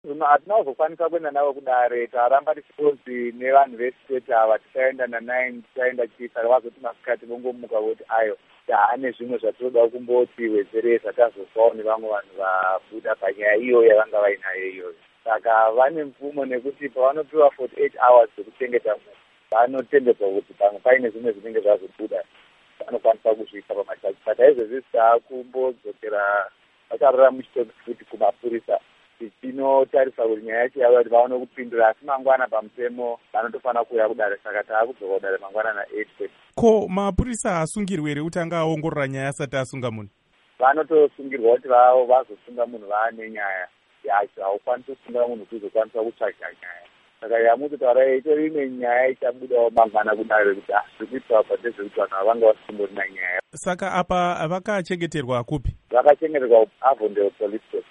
Hurukuro naMuzvinafundo Lovemore Madhuku